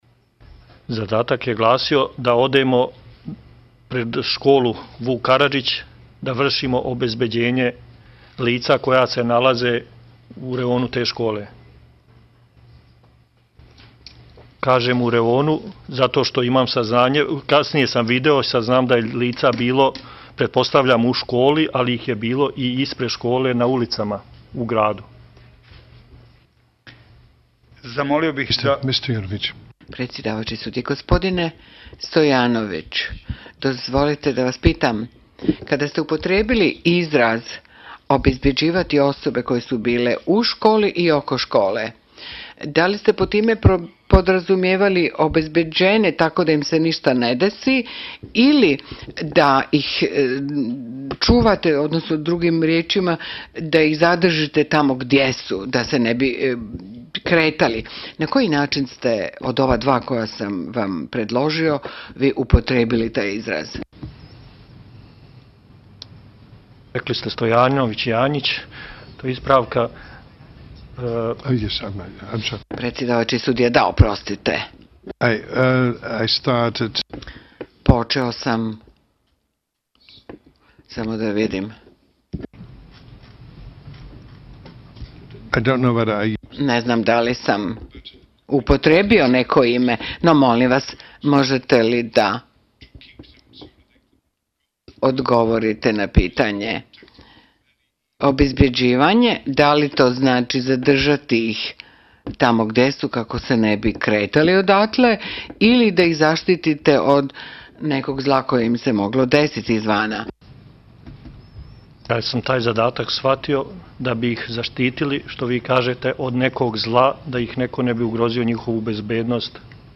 Unakrsno ispitivanje obrane o pucnjavi u školi Vuk Karadžić u Bratuncu